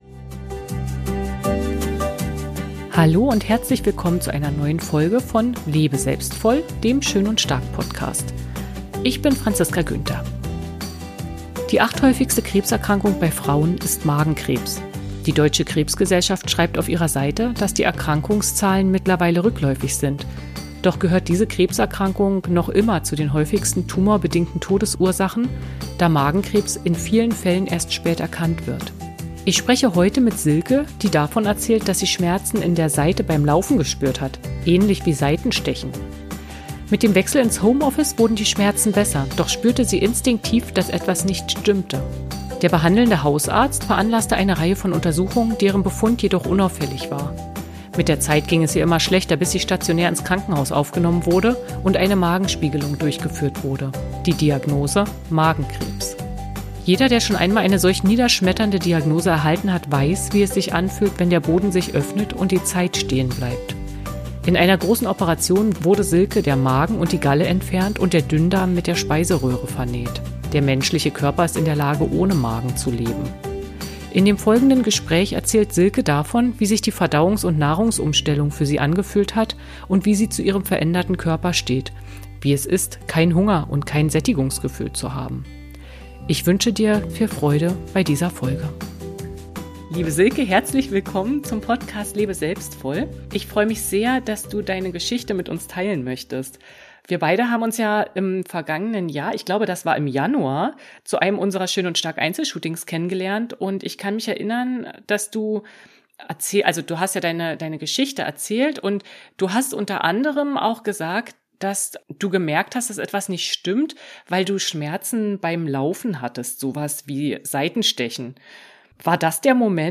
Mein Interviewgast